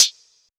Hat
Original creative-commons licensed sounds for DJ's and music producers, recorded with high quality studio microphones.
Short Reverb Closed High Hat Sample D# Key 199.wav
short-reverb-closed-high-hat-sample-d-sharp-key-199-2cP.wav